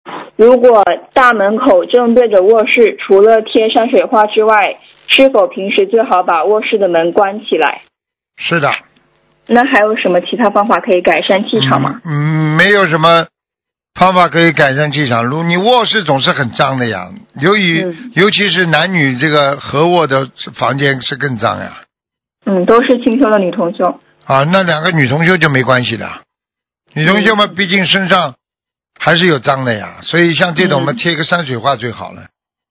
目录：☞ 2019年12月_剪辑电台节目录音_集锦